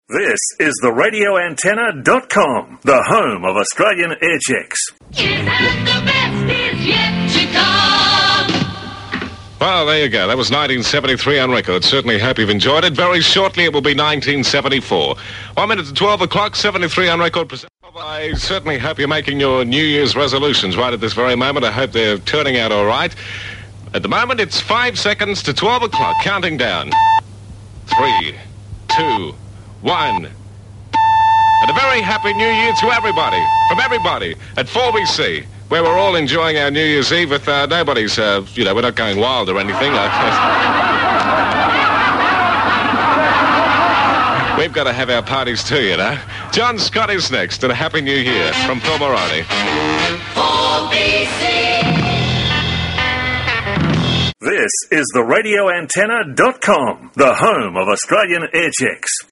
RA Aircheck – 4BC New Year 73-74
RA-Aircheck-4BC-New-Year-73-74.mp3